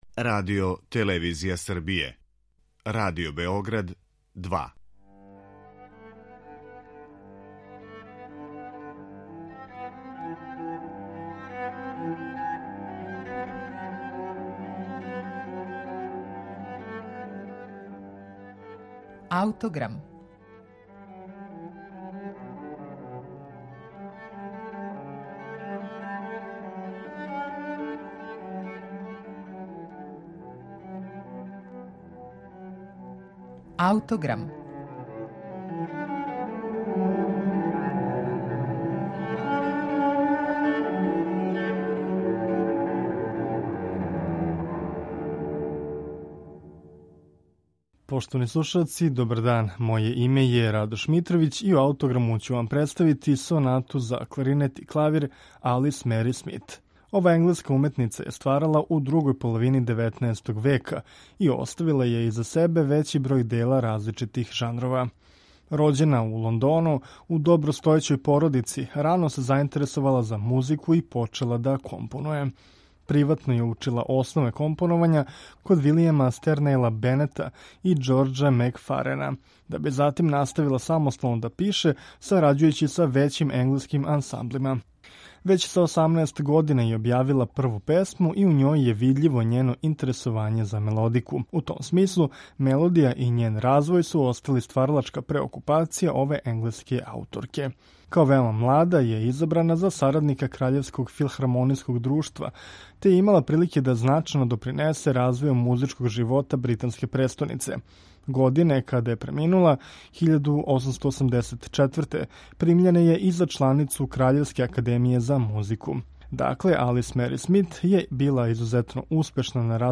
Алис Мери Смит- Соната за кларинет и клавир
Реч је о делу које садржи романтичарску основу, али класицистичку формалну прегледност, при чему су оба инструмента равноправно третирана.